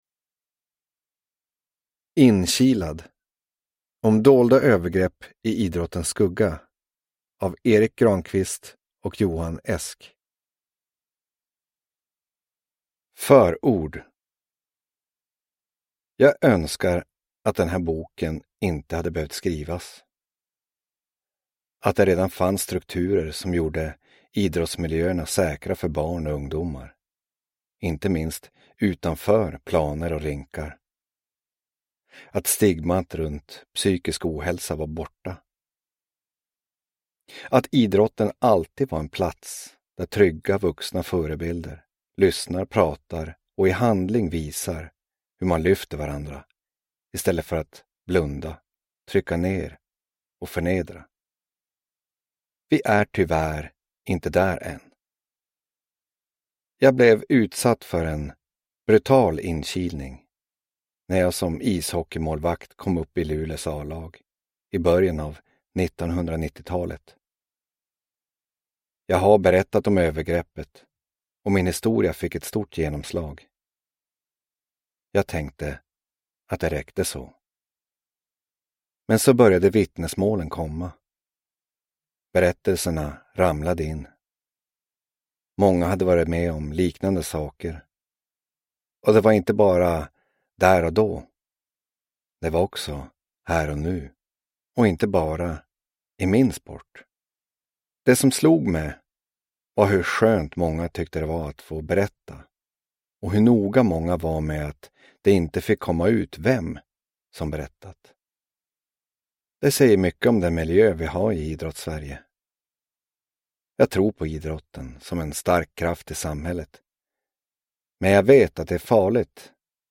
Inkilad : om dolda övergrepp i idrottens skugga – Ljudbok – Laddas ner